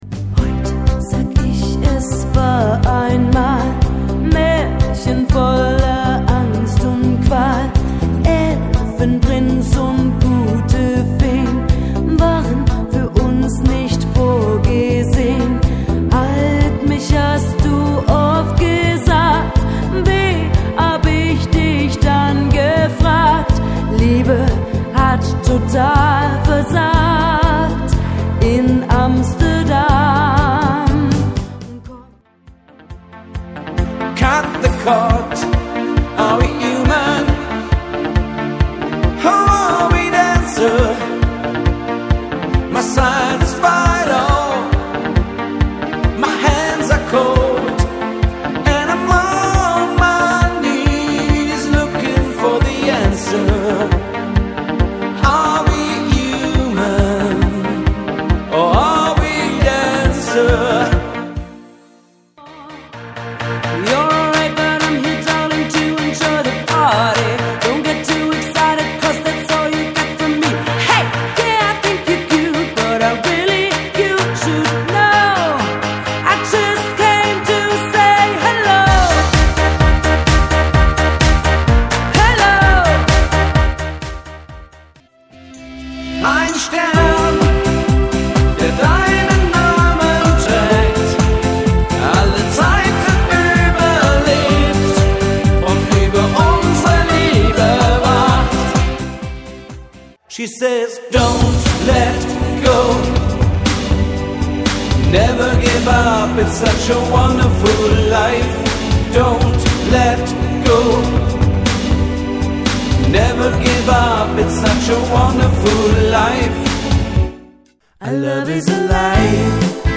• Volksmusik
• Country
• Coverband
• Allround Partyband